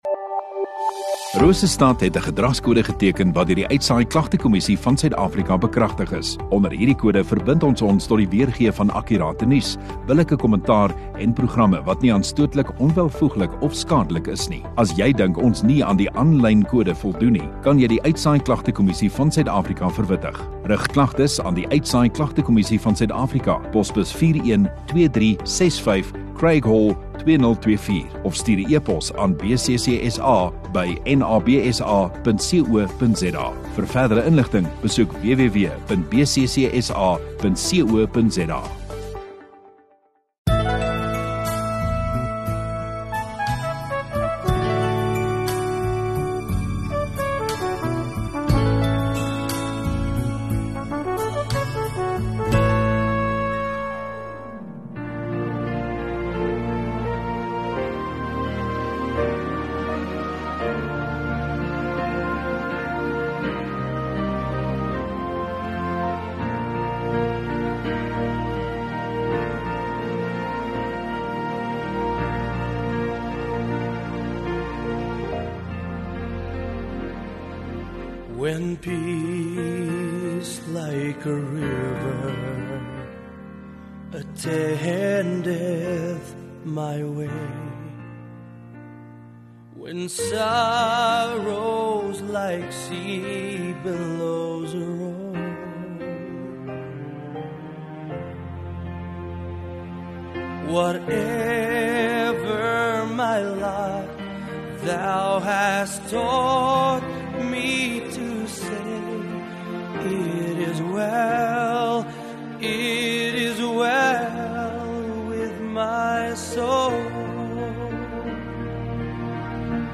6 Jul Sondagaand Erediens